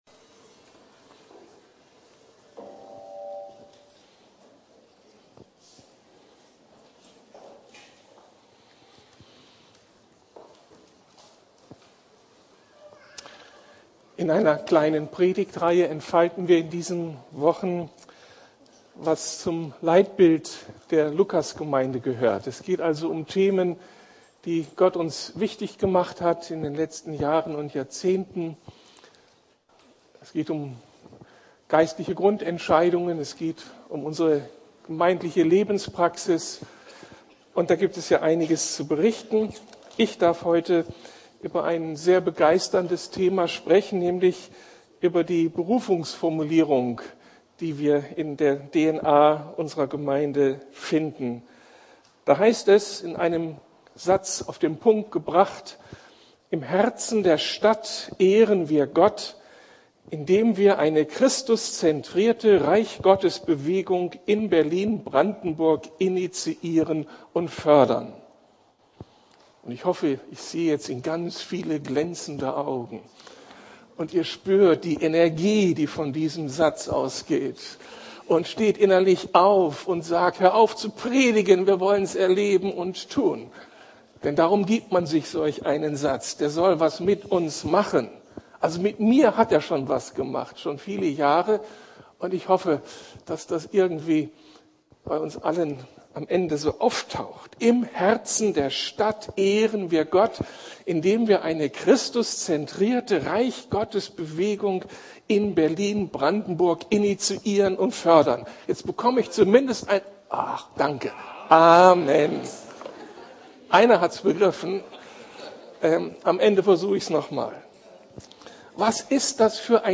Die Berufung der Lukas-Gemeinde ~ Predigten der LUKAS GEMEINDE Podcast